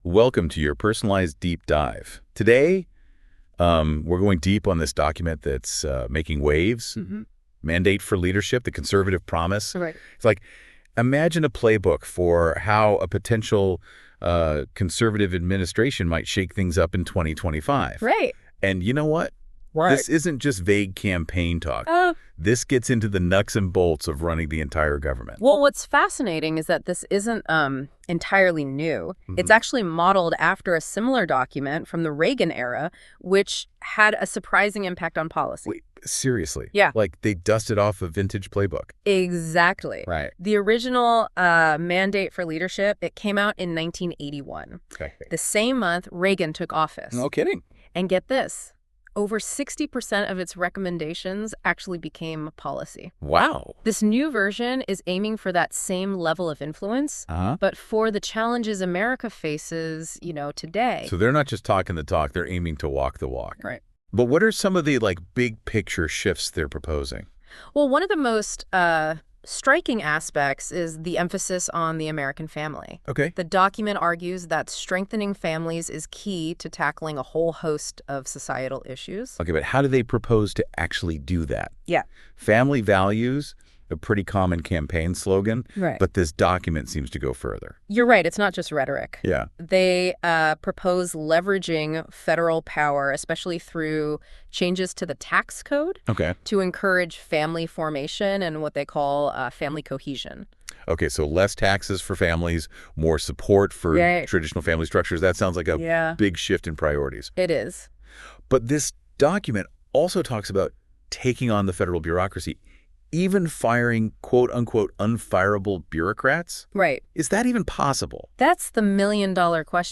For those that dont have time to read 900 pages of Project 2025, here is the AI podcast summary